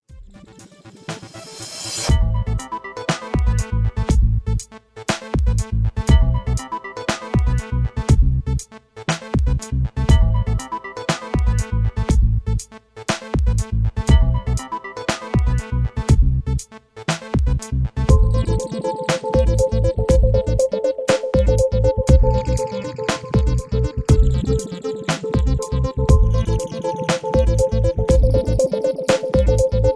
Electro Ambient with feel of tension